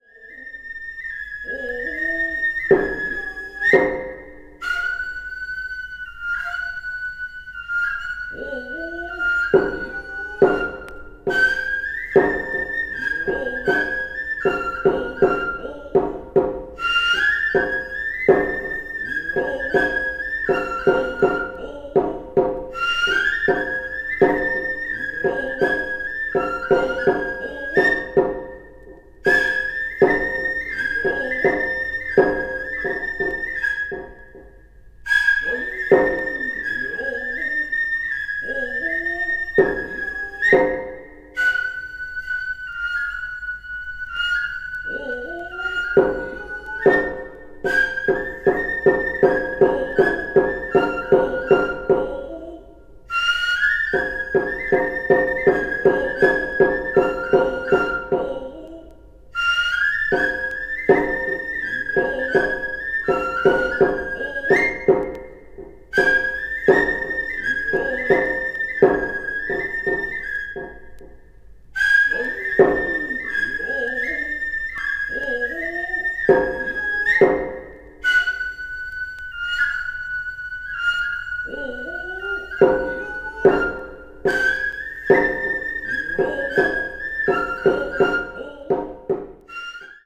media : EX/EX,EX/EX(some slightly noises.)
The sound quality is exceptionally high.
east asia   ethnic music   japan   oriental   traditional